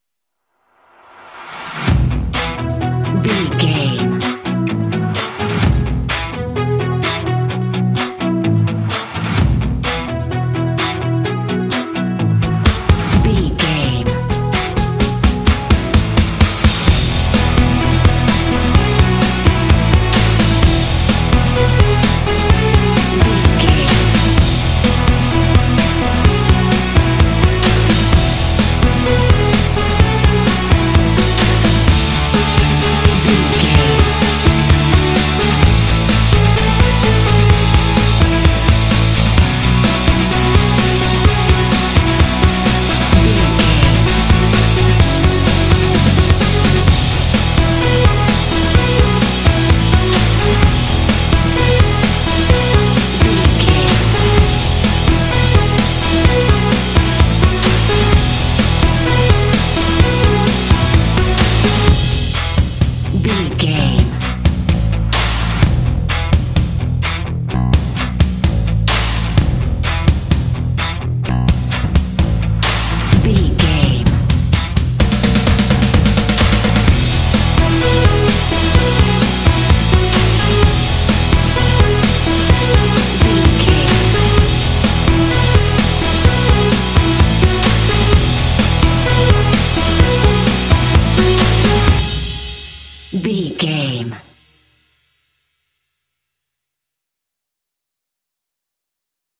Uplifting
Ionian/Major
lively
cheerful/happy
drums
bass guitar
electric guitar
percussion
synthesiser
synth-pop
alternative rock
indie